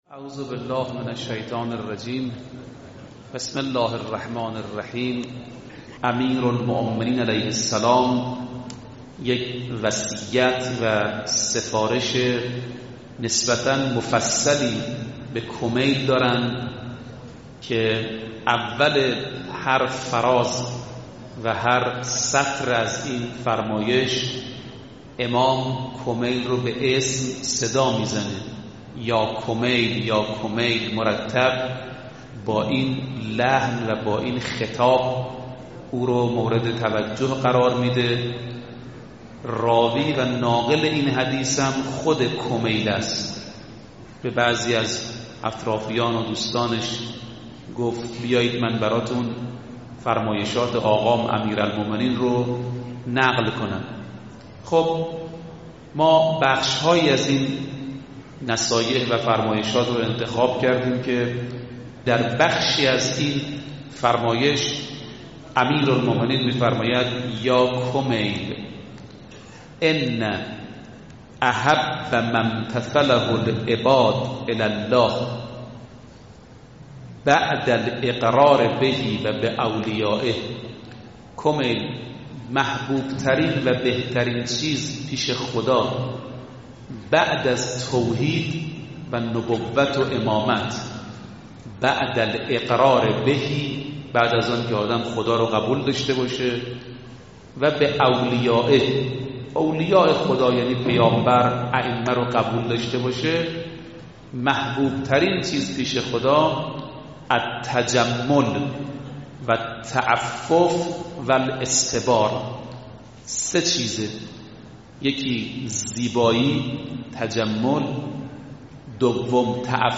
سخنرانی شهادت امام علی